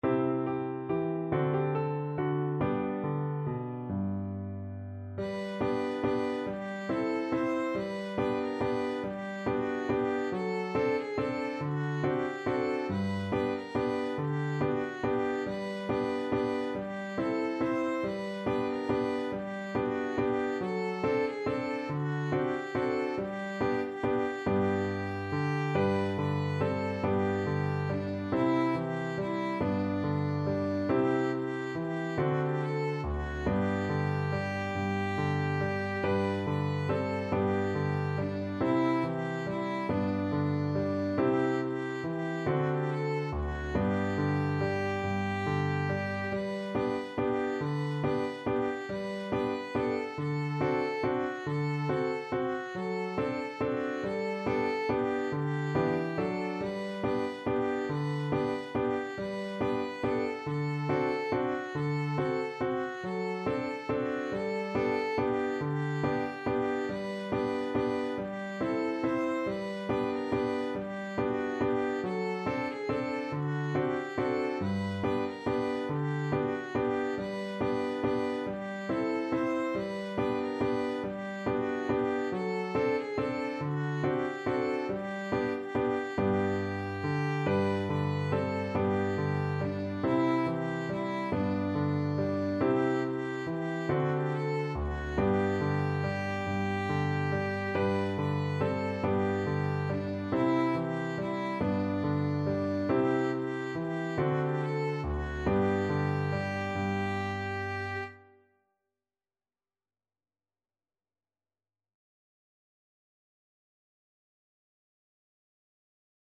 Violin version
Key: G major
Time Signature: 6/8
Tempo Marking: Steadily = 140
Instrument: Violin
Style: Traditional